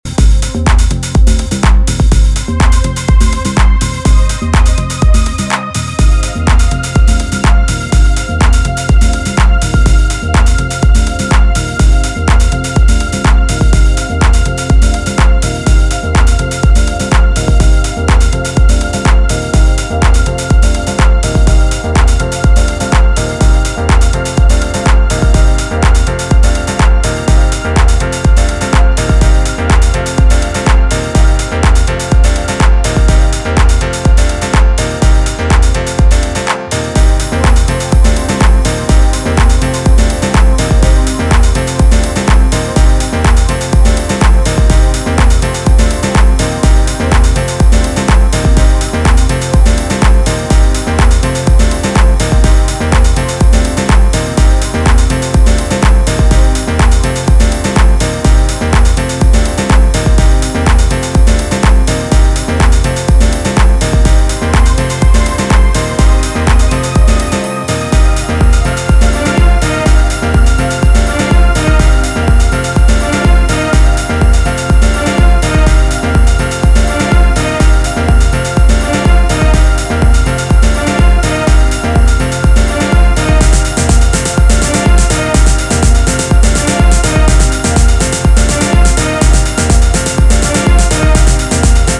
いずれの楽曲もピークタイムを熱く盛り上げてくれそうな仕上がりで、今回も渾身の内容ですね！